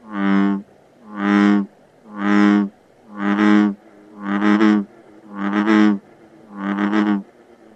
دانلود صدای قورباغه برای زنگ اس ام اس از ساعد نیوز با لینک مستقیم و کیفیت بالا
جلوه های صوتی
برچسب: دانلود آهنگ های افکت صوتی انسان و موجودات زنده دانلود آلبوم صدای قورباغه (قور قور) از افکت صوتی انسان و موجودات زنده